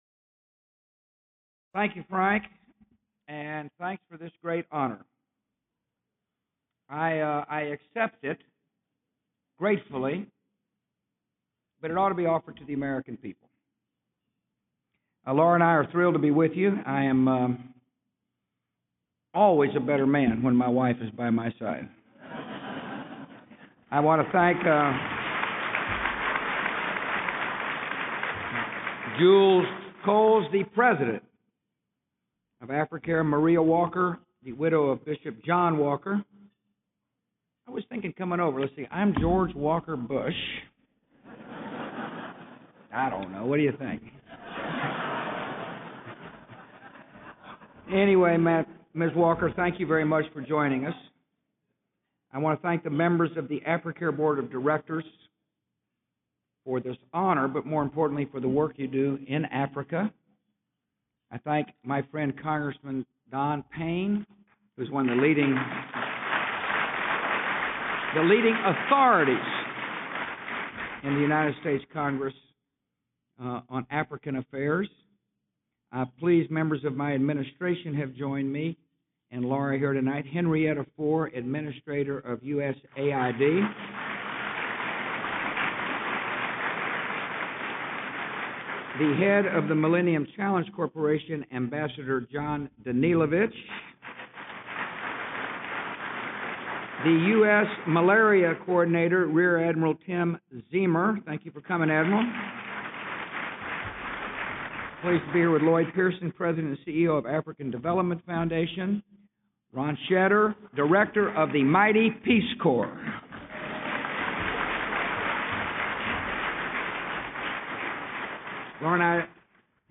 U.S. President George W. Bush speaks at the 2008 Bishop John T. Walker Memorial Dinner
President George W. Bush speaks at the 2008 Bishop John T. Walker Memorial Dinner at the Washington Hilton Hotel in Washington D.C.